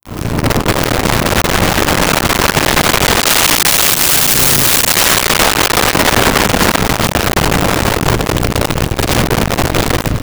Motorcycle Fast By 01
Motorcycle Fast By 01.wav